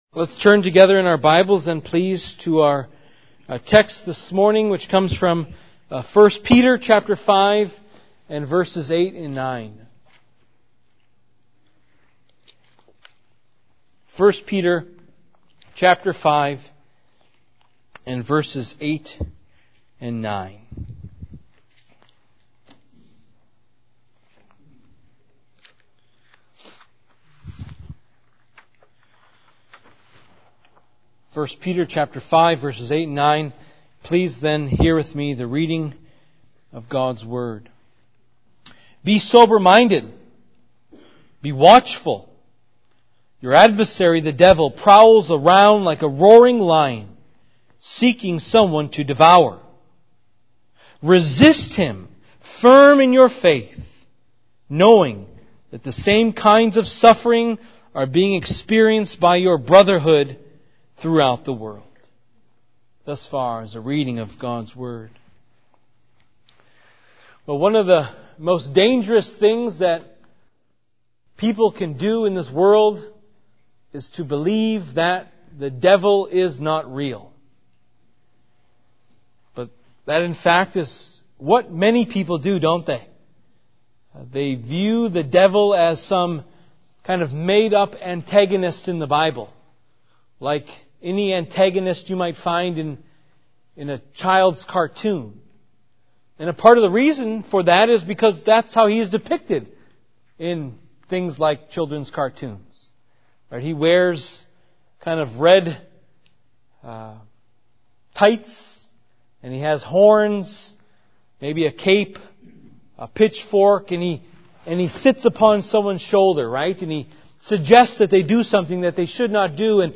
“The Devil Is Real” sermon (1 Peter 5:8-9) – Covenant Baptist Church New Berlin, WI